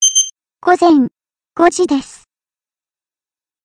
音声で時報をお知らせします。